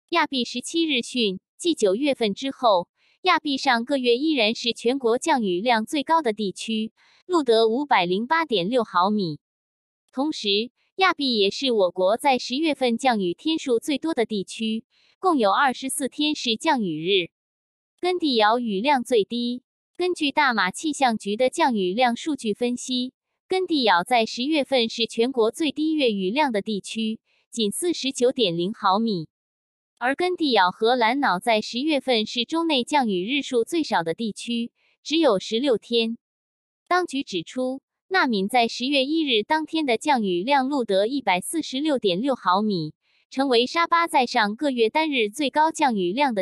kk-raining.mp3